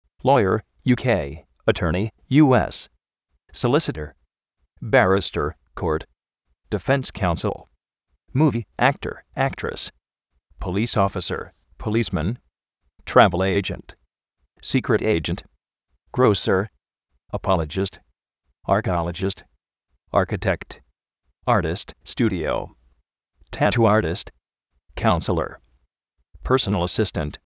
lóier (UK), atórni (US)
solícitor
bárrister (kó:rt)
dífens kaúnsel
(múvi) áktor, áktris